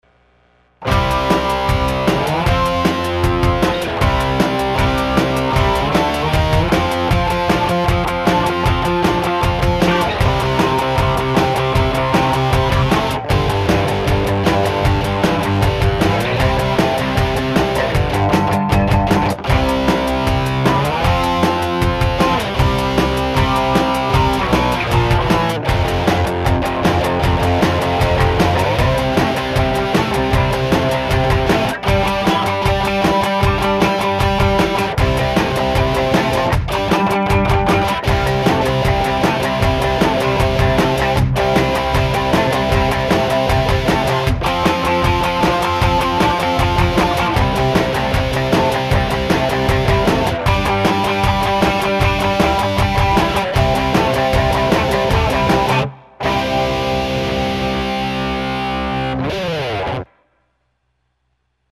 VH-401よりパワーがあってレスポンスが良くノイズが少ない。
MG搭載のピックアップと比べると、パワー感はやや劣る。
DigiOnSoundとZOOM GFX-707のみ使用。SoundEngine Freeでマスタリング。歪みはゲインを上げている為、ギターのボリュームを絞って音撮り。クリーンはセンター。
EMG HZ H4結構歪めても音が潰れる事は無かった！
EMG81より低音はあるので良しとしましょう！